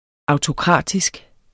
Udtale [ ɑwtoˈkʁɑˀdisg ]